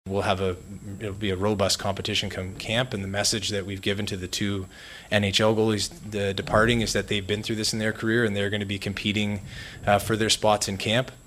Penguins president of hockey operations Kyle Dubas took questions for over half an hour yesterday in his season-ending news conference, and while he is certain he has the team on the right track, he hinted it might be another year before fans see the sort of Penguins Stanley Cup-contending team they are used to.